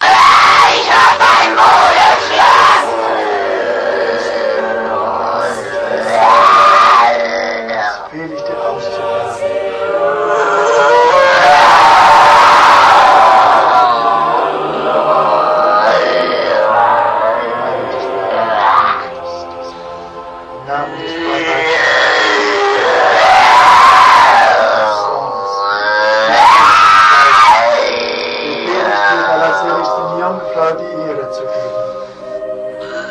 Звуки дьявола, черта
Изгнание демона из одержимого (экзорцизм)